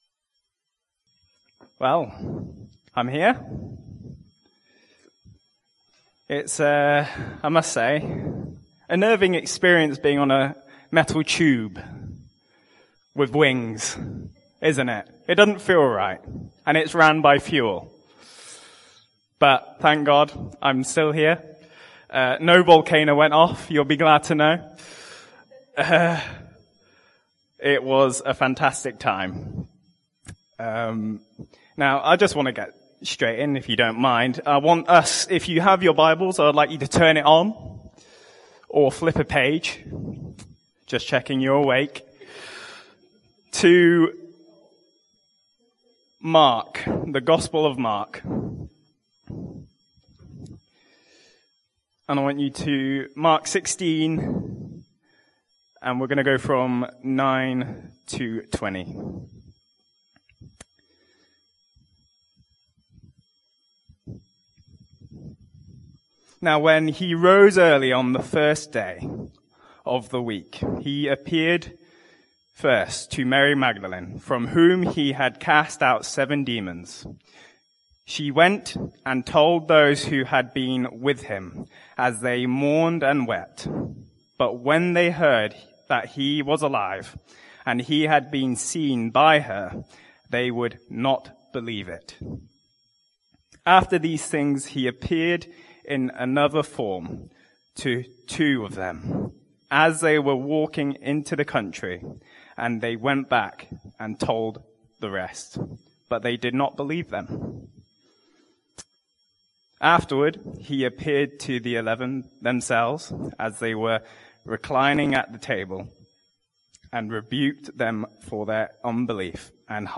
(Apologies for the poor sound quality)
The most recent Sunday messages will be available on this page if you have not been able to get to a meeting or would like to listen to the message again.